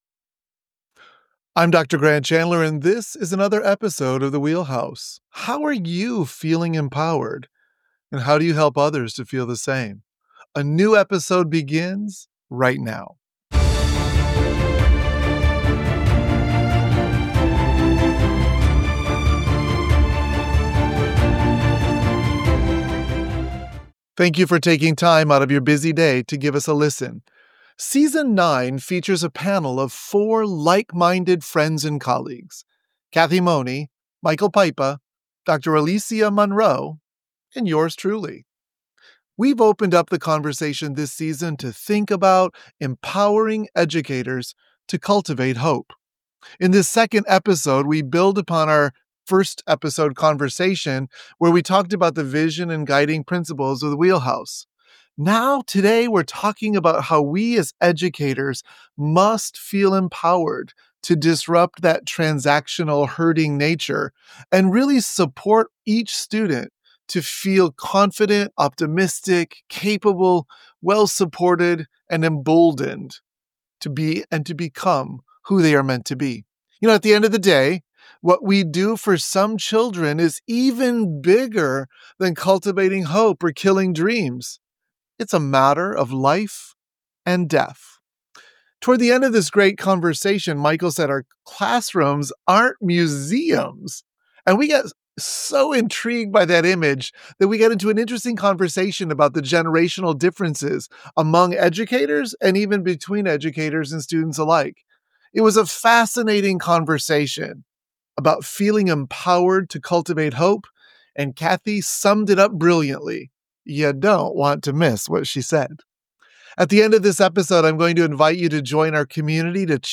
In the second episode of Season Nine, the panel engages in a profound discourse concerning the empowerment of educators and the vital need to cultivate hope within educational environments.